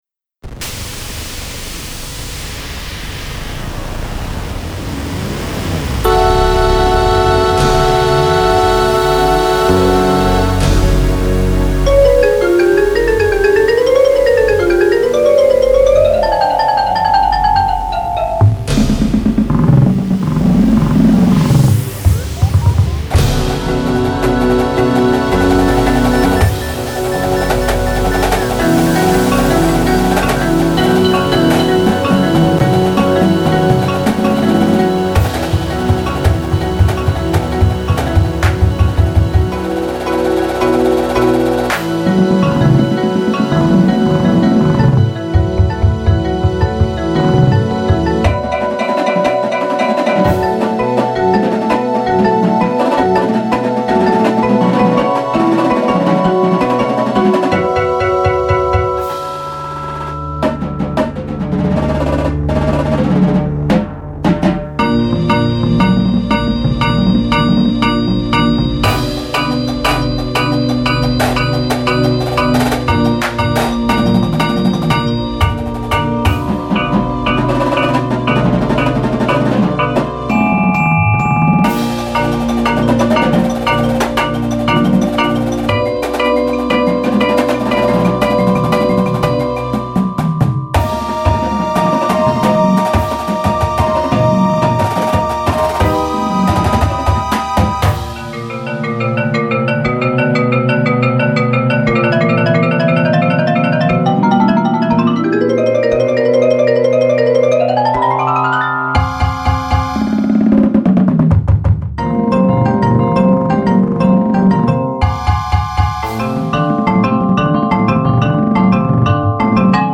Style: Modern – Symphonic - Edgy
• Bells/Glockenspiel
• Xylophone
• 2 Vibraphones (4 option)
• 2 Marimbas (4 option)
• 2 Synthesizers
• Snareline
• Tenorline (5 and 6 drum options)
• Bassline (4 and 5 drum options)